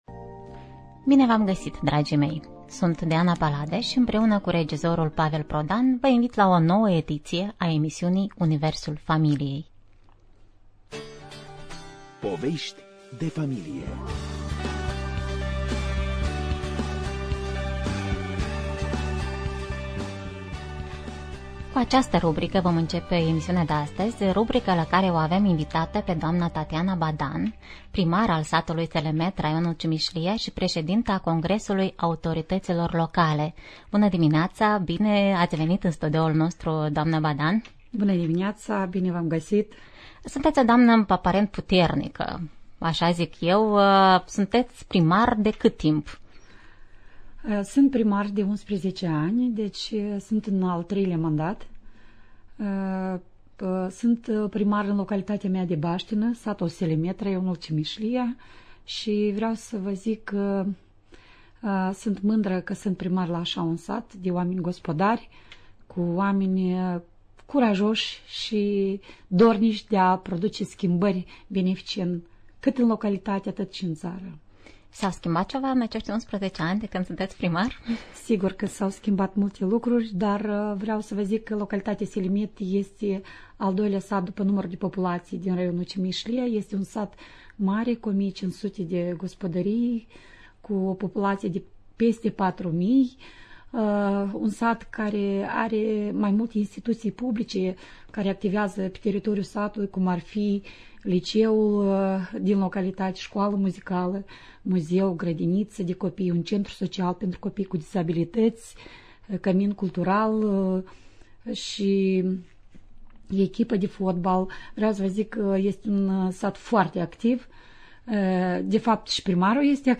Echilibrul dintre ascensiunea profesională şi armonia vieţii familiale. Invitată în studio – Tatiana Badan, primar al satului Selemet, raionul Cimişlia, Președintele Congresului Autorităților Locale din Moldova * Familia şi cariera.
Sondaj cu ascultătorii